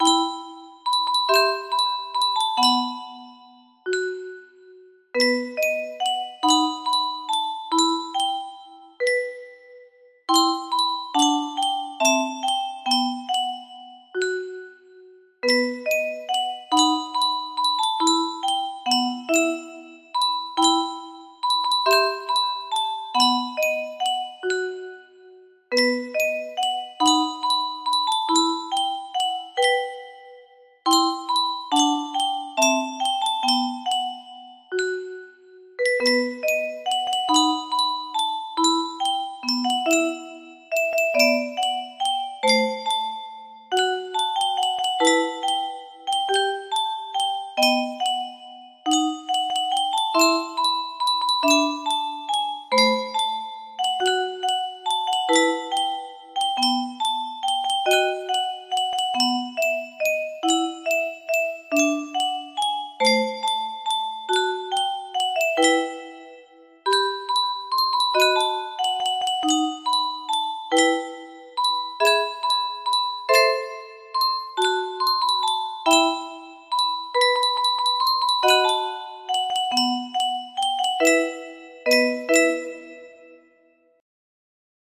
La Bruja music box melody